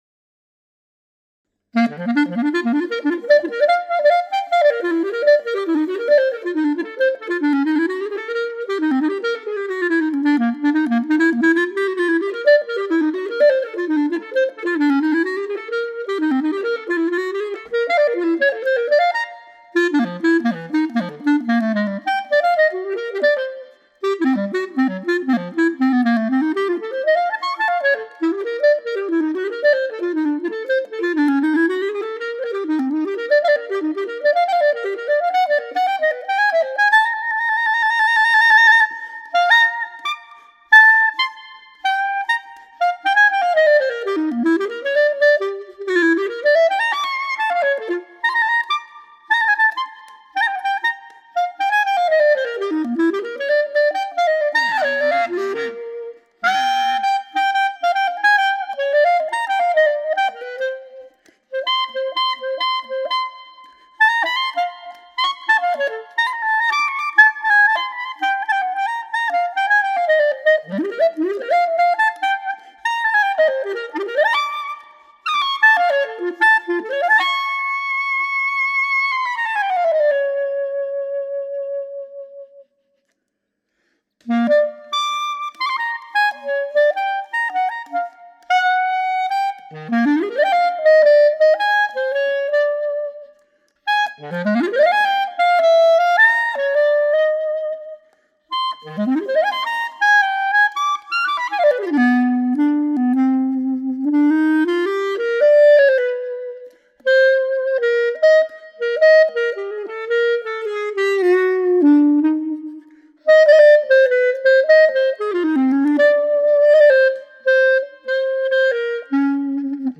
(Jazz) Advanced Level